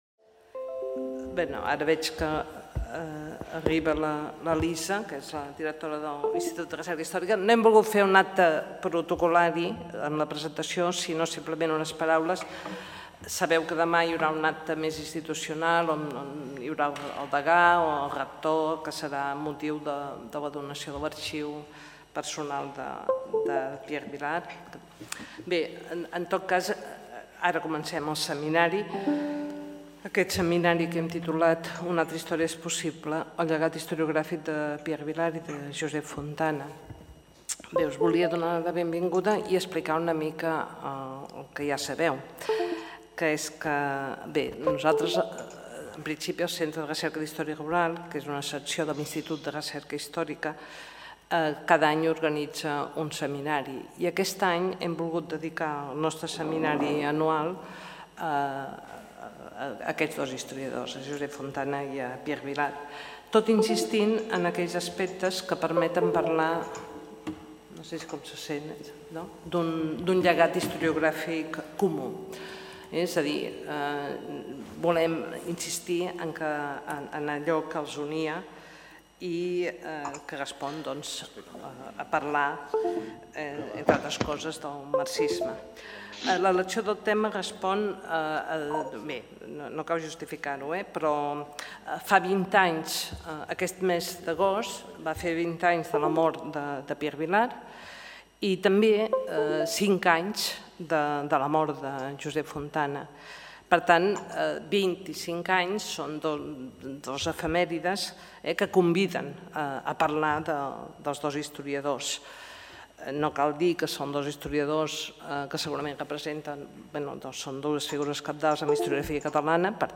Presentació del Seminari